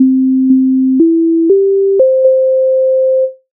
MIDI файл завантажено в тональності G-dur